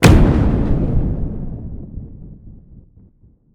chosen_one_sound_effect.mp3